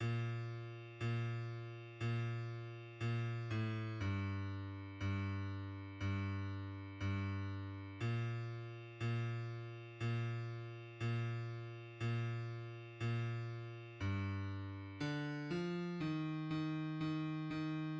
{\clef bass \tempo 4 = 120 \key bes \major bes,2 bes, bes, bes,4 a, g,2 g, g, g, bes, bes, bes, bes, bes, bes, g, d4 f ees ees ees ees}\midi{}